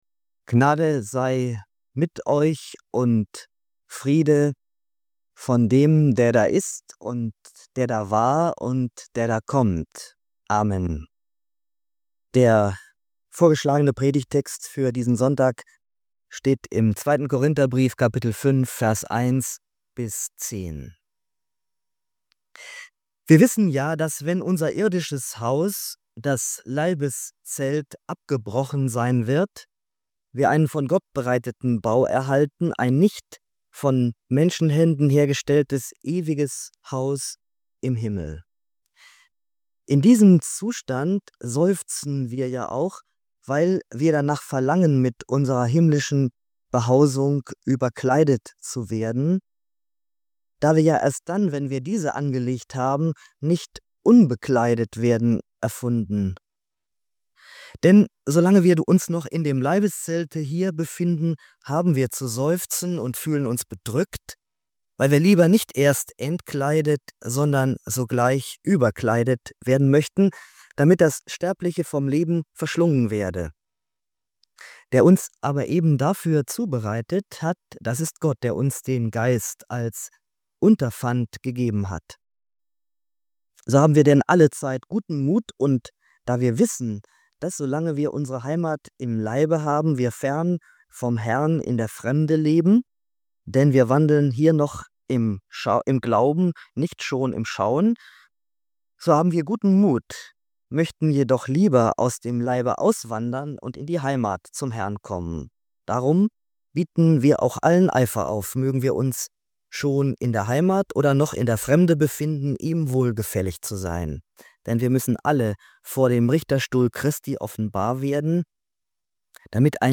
In dieser Predigt zu 2. Korinther 5,1–10 geht es um die Zukunft des Glaubens: um das „irdische Zelt“ unseres Leibes, das vergeht, und das ewige Haus bei Gott, das schon bereitsteht.